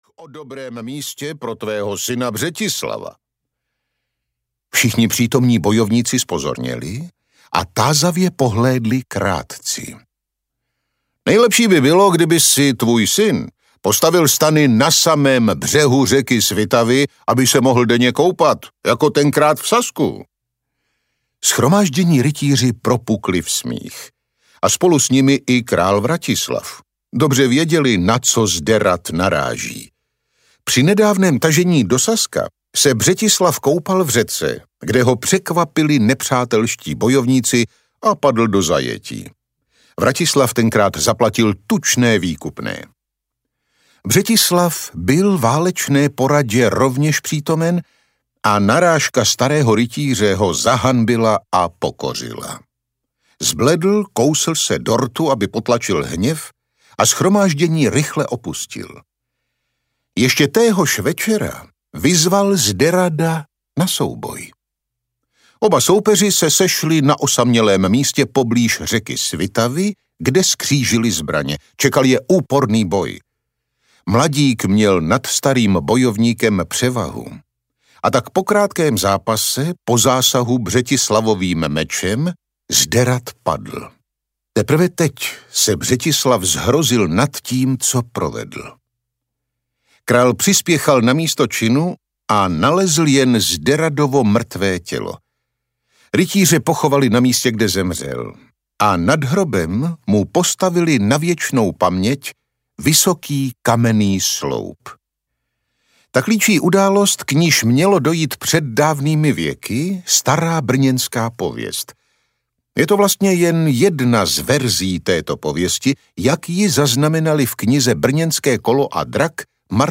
Krvavý úterek audiokniha
Ukázka z knihy